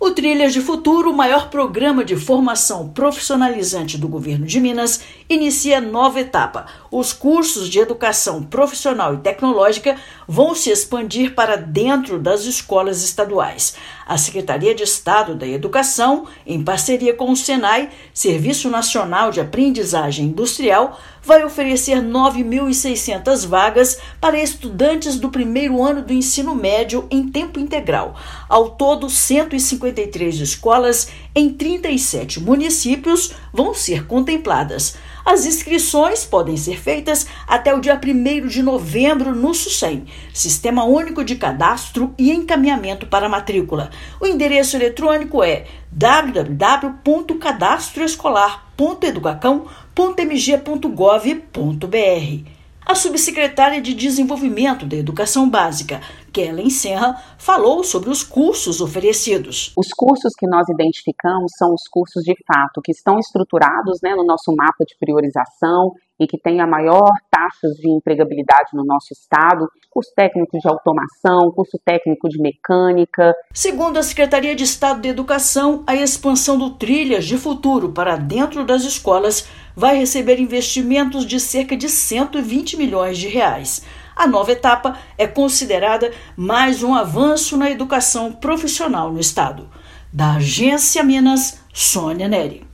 Inscrições têm início nesta terça-feira (1/10) por meio do Sistema Único de Cadastro e Encaminhamento para Matrícula para estudantes que pretendem ingressar no Ensino Médio em Tempo Integral. Ouça matéria de rádio.